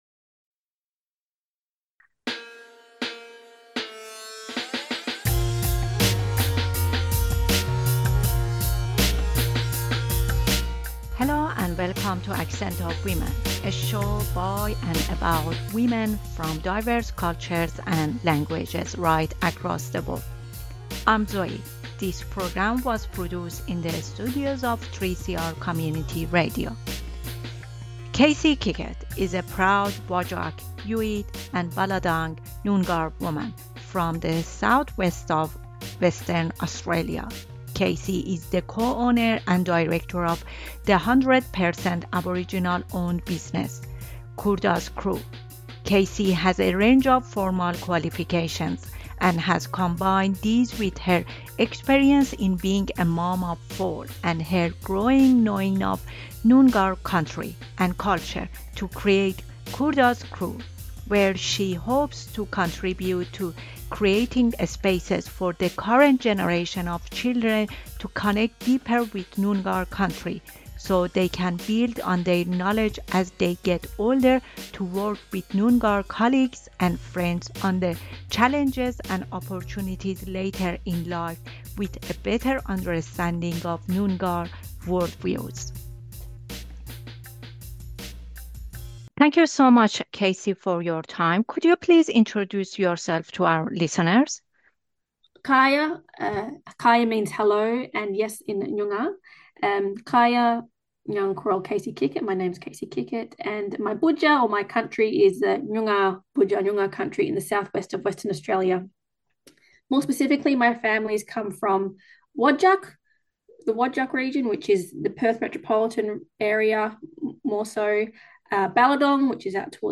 Tweet Accent of Women Tuesday 8:30am to 9:00am A program by and about women from culturally and linguistically diverse backgrounds.